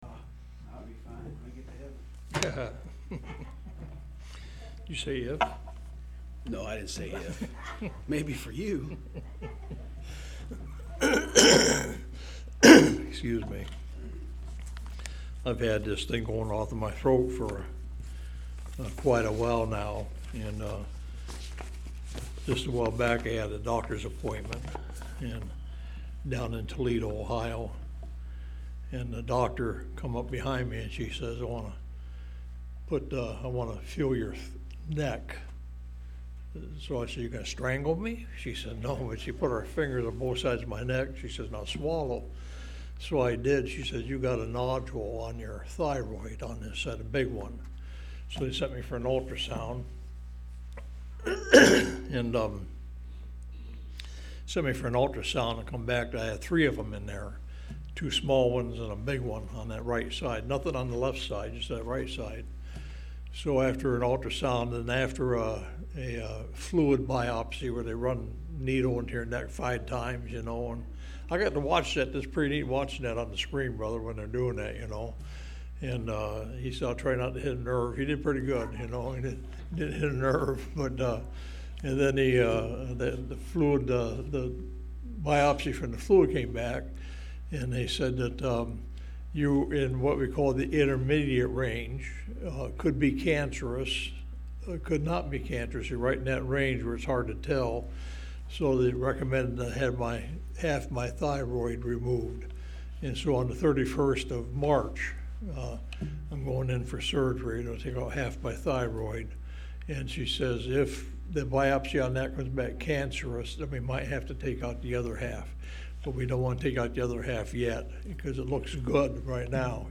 Online Sermons – Walker Baptist Church
02-02-25 Morning Service